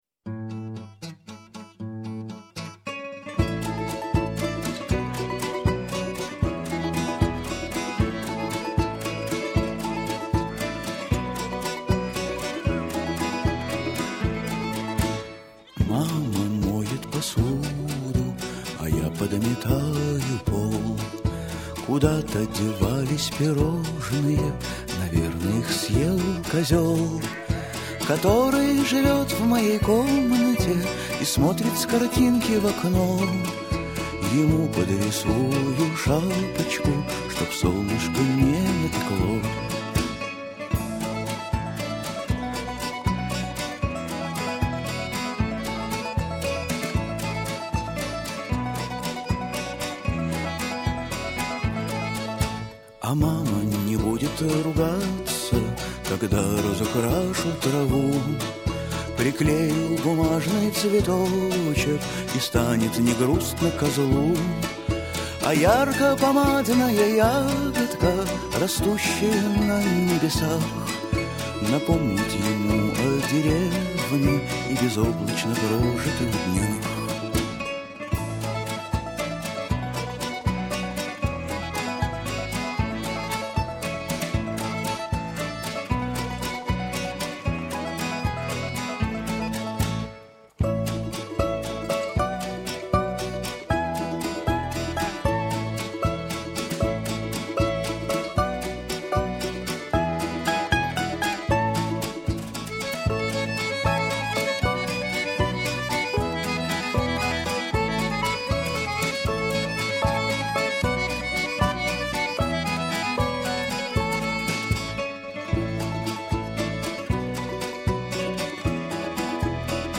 песни для детей